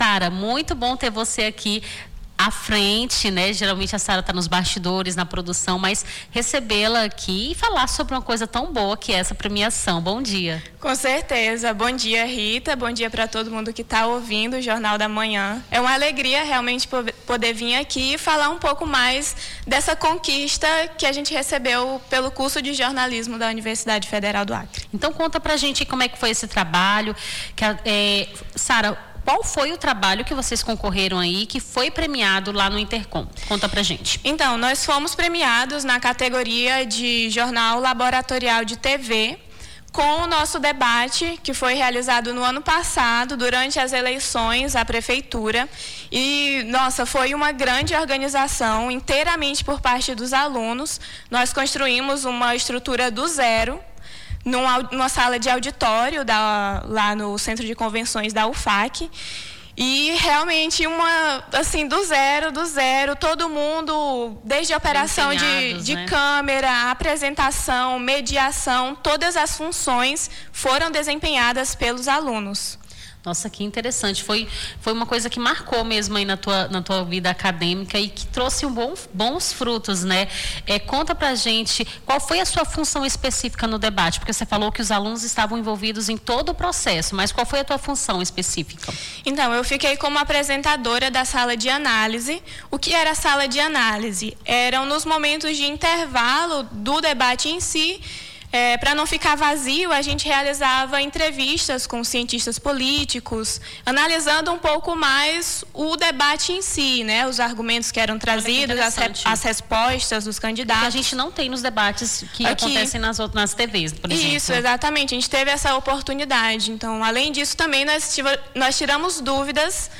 Nome do Artista - CENSURA - ENTREVISTA (DEBATE ELEITORAL EXPOCOM) 19-09-25.mp3